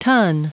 Prononciation du mot ton en anglais (fichier audio)
Prononciation du mot : ton